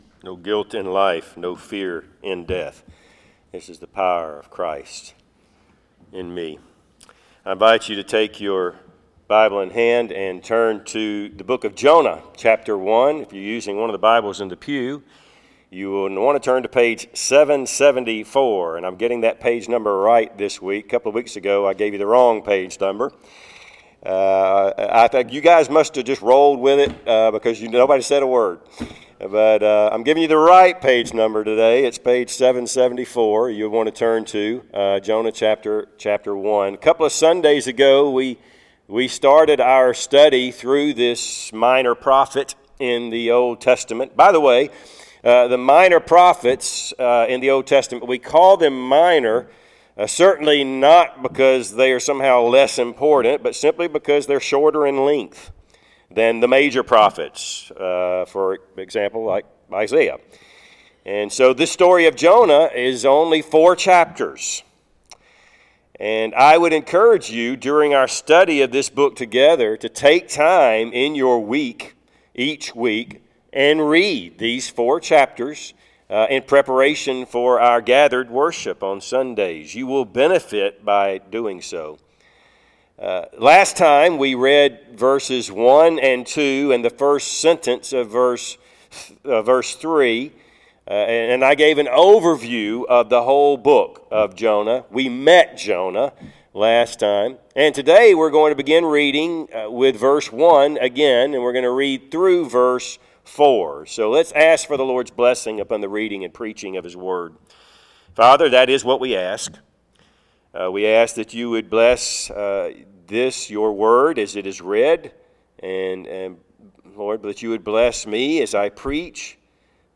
Passage: Jonah 1:1-4 Service Type: Sunday AM